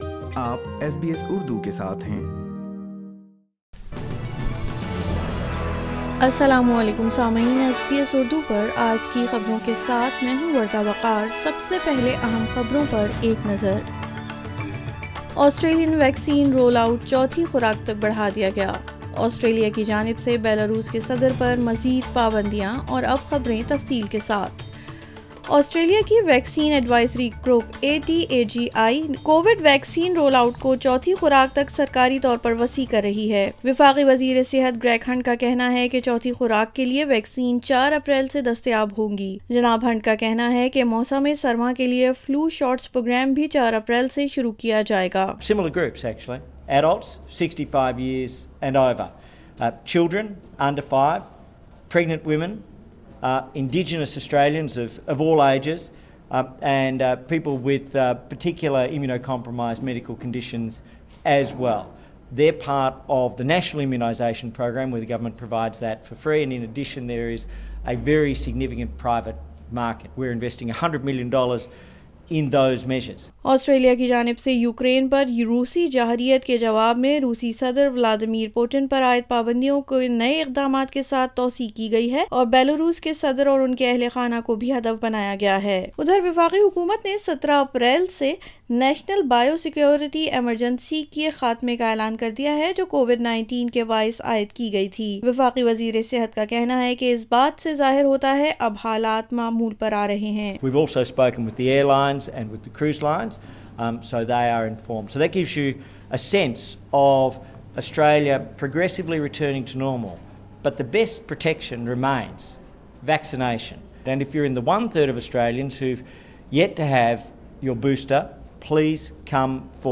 SBS Urdu News 25 March 2022